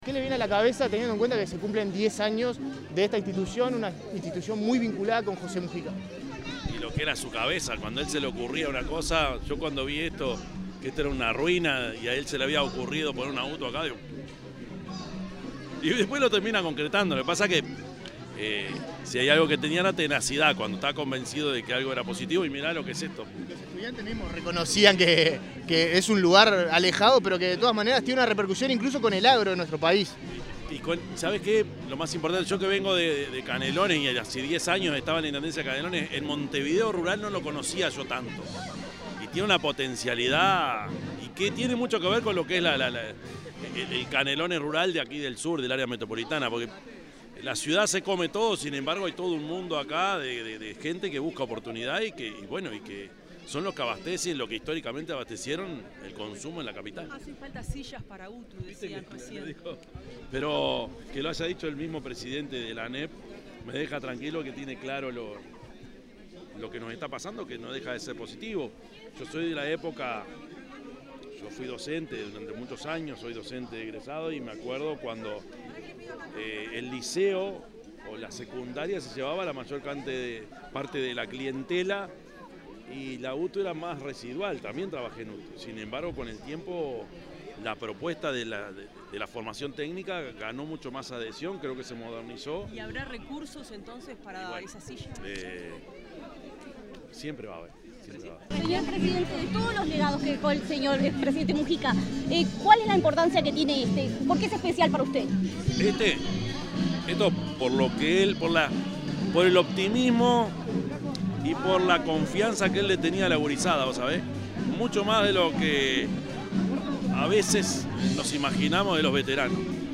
Declaraciones del presidente Yamandú Orsi
Declaraciones del presidente Yamandú Orsi 02/09/2025 Compartir Facebook X Copiar enlace WhatsApp LinkedIn Tras participar en el 10.° aniversario de la Escuela Agraria Montevideo, anexo Rincón del Cerro, el presidente de la República, Yamandú Orsi, dialogó con la prensa sobre el proyecto educativo y el rol del exmandatario José Mujica.